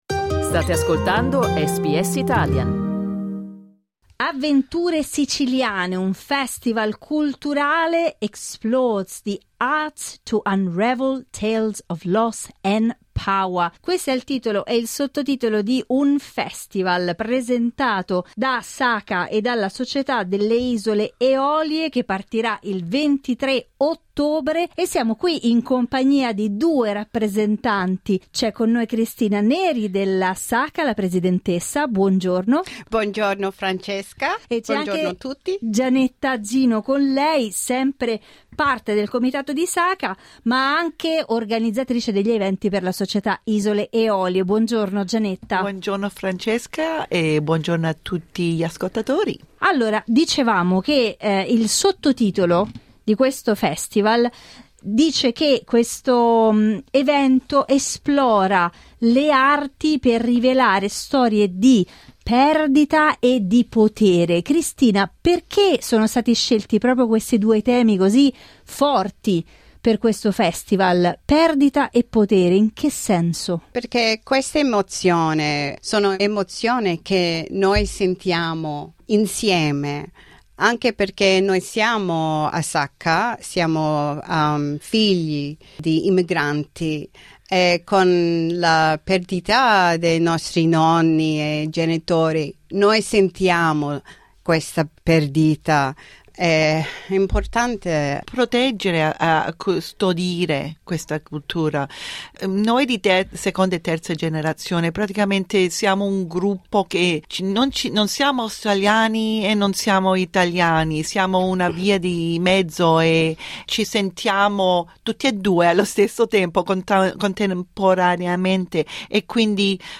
Ascolta l'intervista cliccando sul tasto "play" in alto Ascolta SBS Italian tutti i giorni, dalle 8am alle 10am.